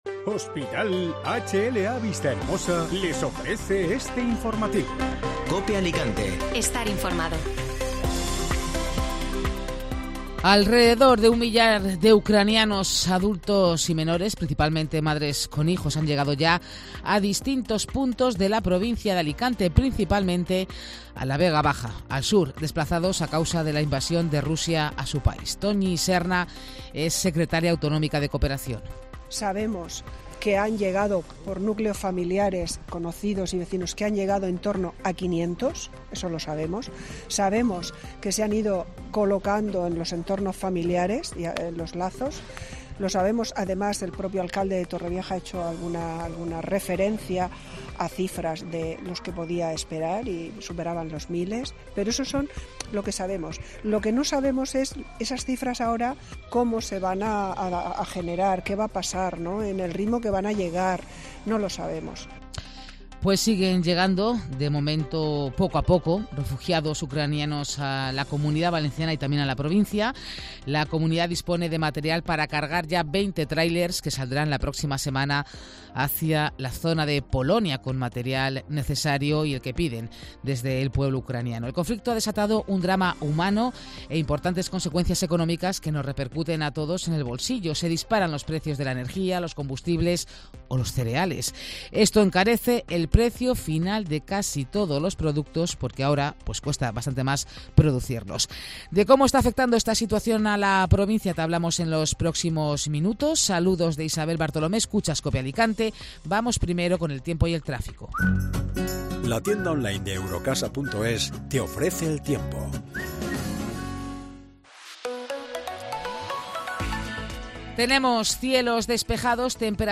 Escucha las noticias de este jueves en Alicante y provincia. Sigue la solidaridad con el pueblo ucraniano.